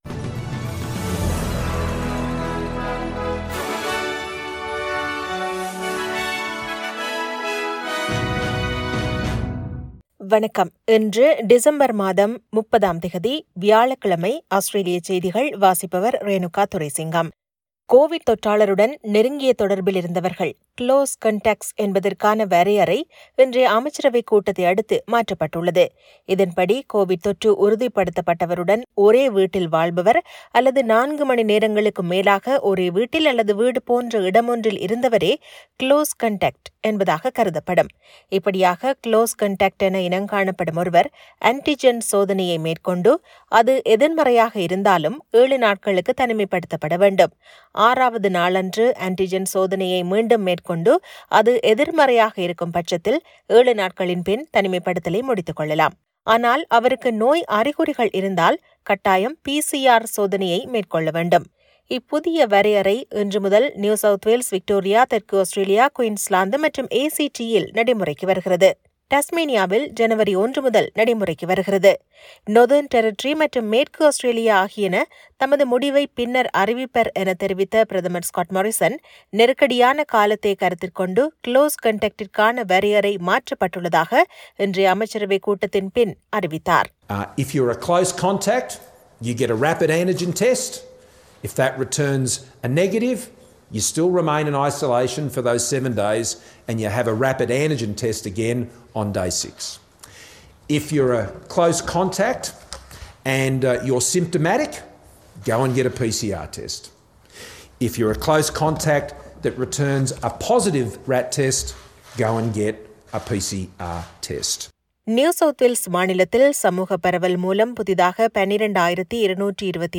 Australian news bulletin for Thursday 30 December 2021.